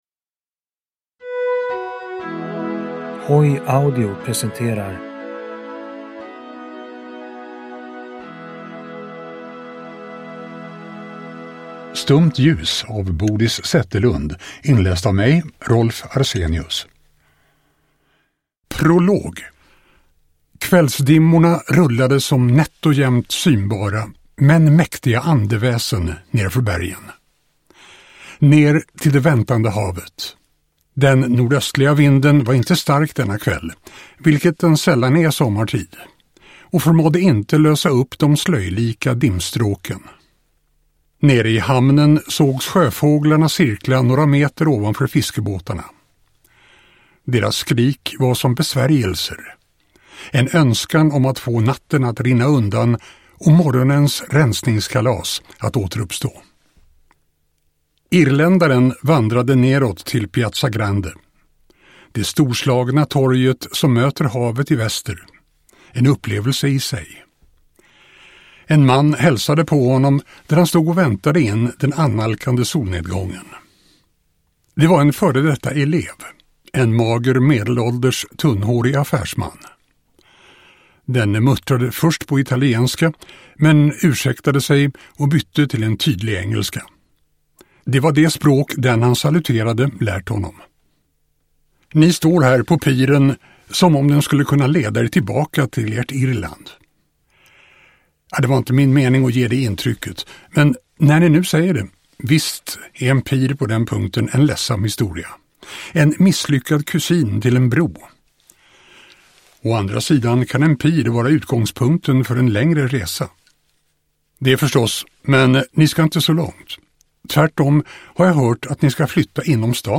Stumt ljus (ljudbok) av Boris Zetterlund